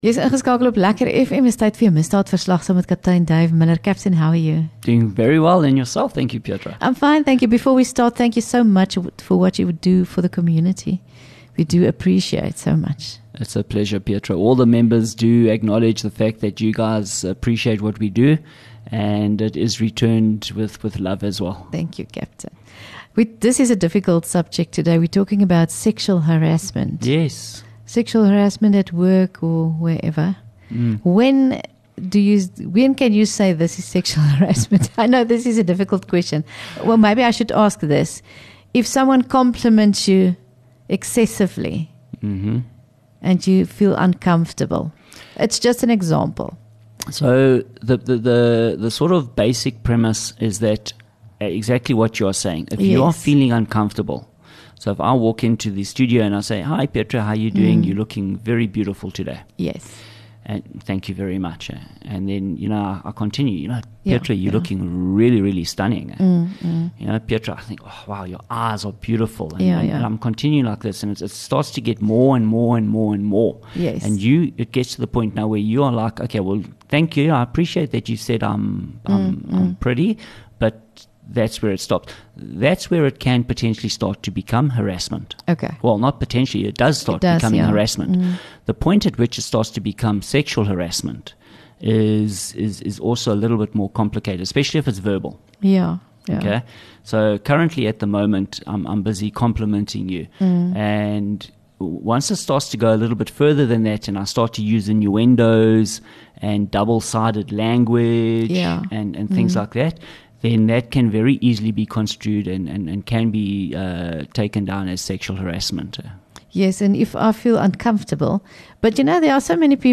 LEKKER FM | Onderhoude 11 Jun Misdaadverslag